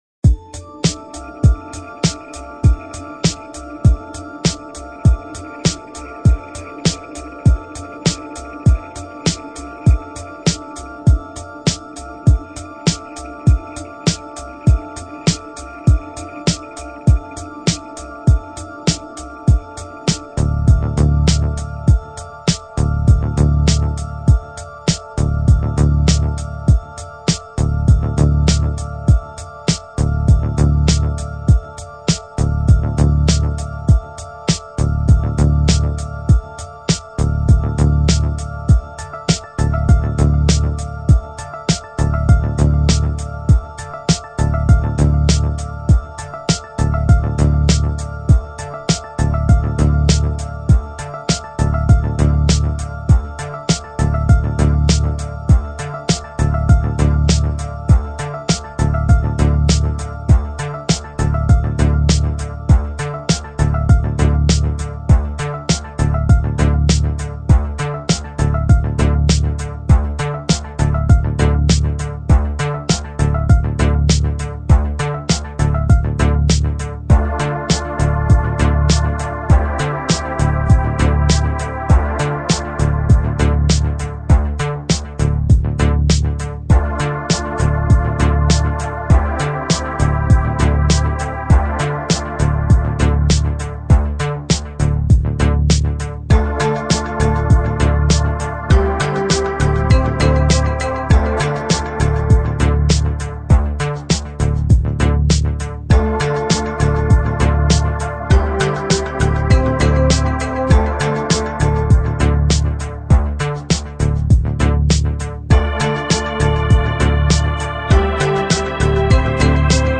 音乐类型：Lo-Fi/Lounge/Chill Out/Downtempo